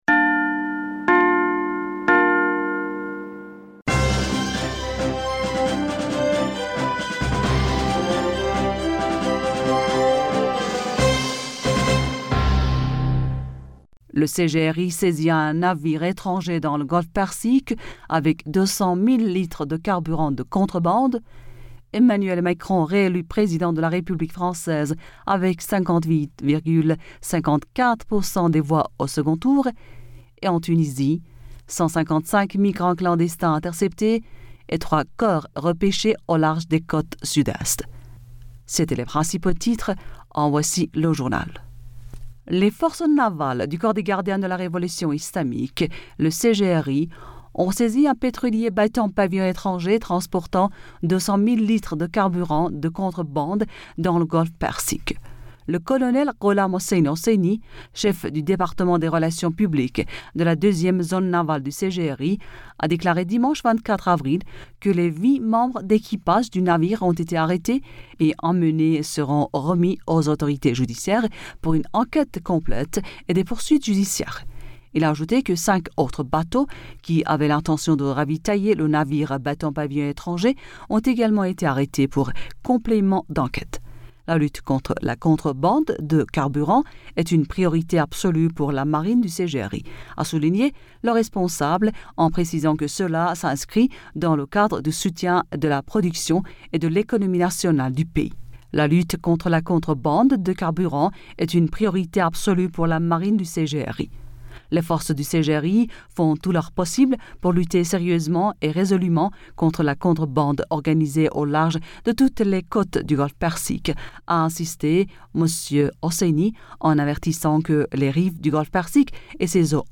Bulletin d'information Du 25 Avril 2022